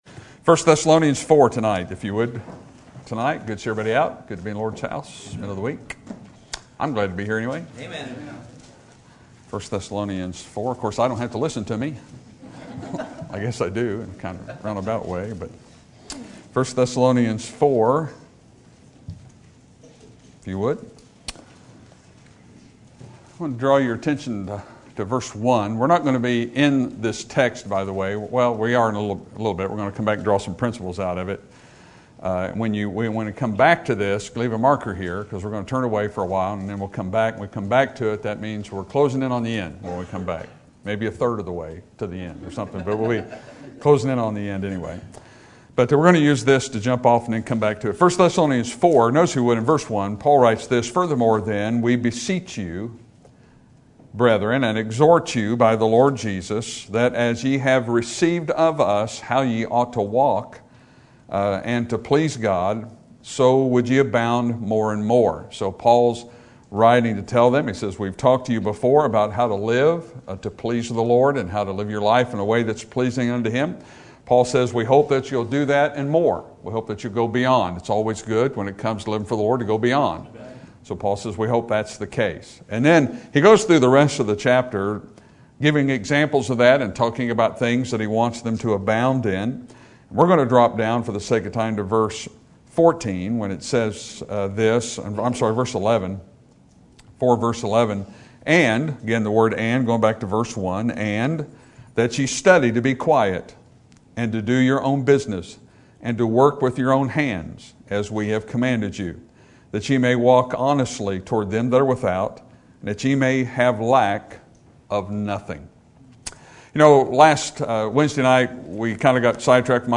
Sermon Topic: General Sermon Type: Service Sermon Audio: Sermon download: Download (30.98 MB) Sermon Tags: 1 Thessalonians Children Work Value